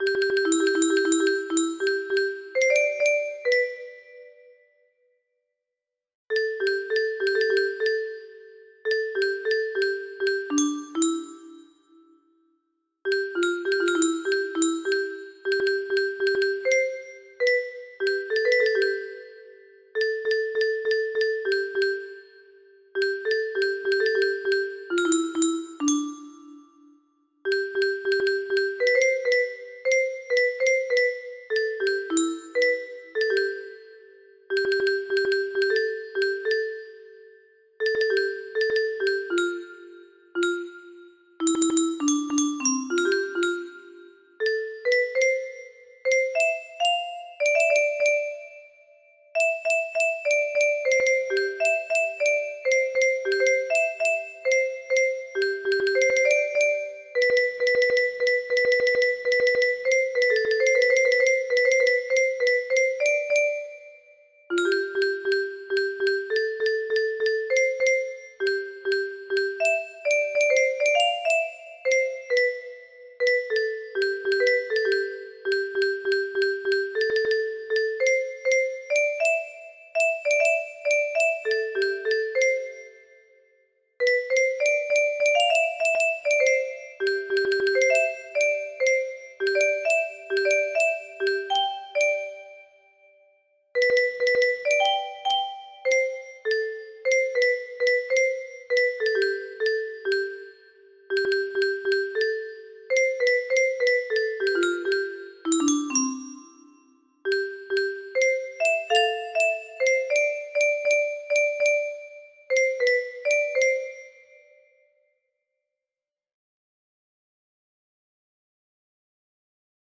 Unknown Artist - Untitled music box melody
Wow! It seems like this melody can be played offline on a 15 note paper strip music box!